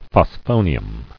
[phos·pho·ni·um]